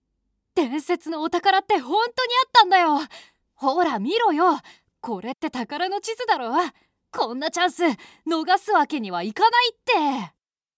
【声優ボイスサンプル】
ボイスサンプル5（少年）[↓DOWNLOAD]